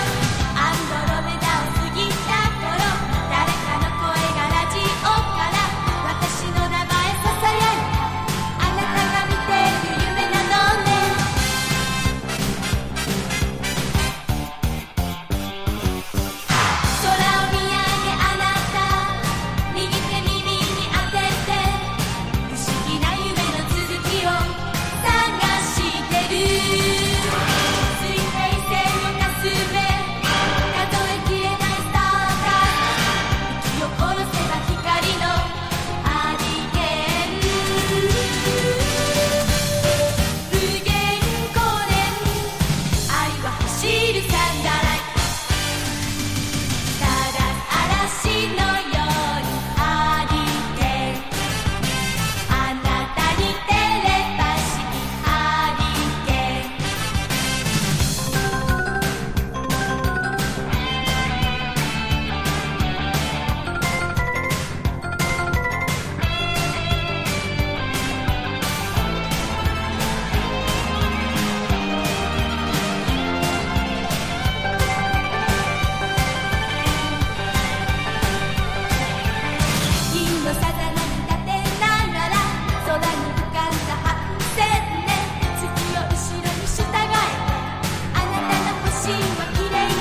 ポピュラー# TECHNO POP# 70-80’S アイドル